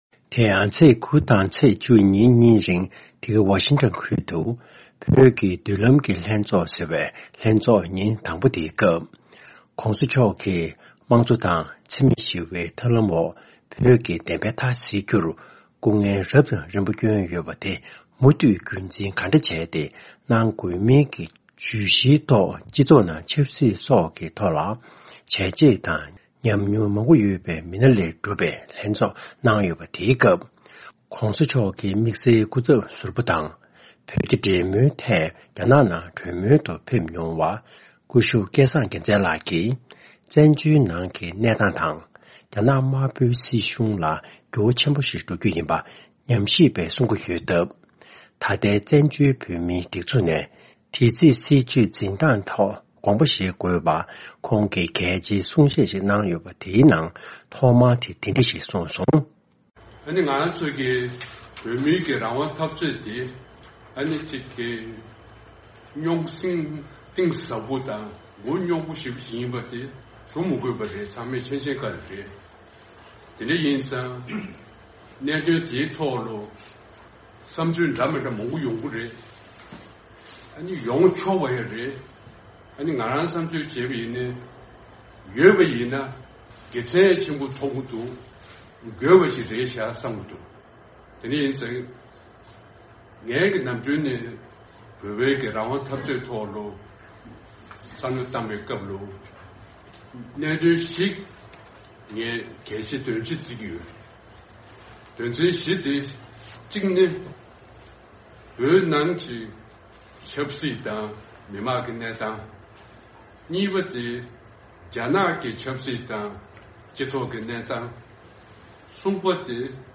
བོད་ཀྱི་མདུན་ལམ་གྱི་ལྷན་ཚོགས་ཐོག་དམིགས་བསལ་སྐུ་ཚབ་ཟུར་པ་སྐལ་བཟང་རྒྱལ་མཚན་ལགས་ཀྱིས་གསུང་བཤད་ཀྱི་ནང་དོན།
དེ་ག་ཨ་རིའི་རྒྱལ་ས་ཝ་ཤིང་ཀྲོན་དུ་བོད་ཀྱི་མདུན་ལམ་གྱི་ལྷན་ཚོགས་སྐབས་བོད་རྒྱ་གཉིས་ཀྱི་འབྲེལ་མོལ་དམིགས་བསལ་སྐུ་ཚབ་ཟུར་པ་སྐུ་ཞབས་སྐལ་བཟང་རྒྱལ་མཚན་ལགས་ཀྱིས་བོད་དོན་འཐབ་རྩོད་ཐད་བསམ་བློ་གསར་པ་དང་ཐབས་ཇུས་རྒྱ་ཆེན་པོ་ཞིག་བཞེས་དགོས་པའི་དགོངས་ཚུལ་གསུངས་པ།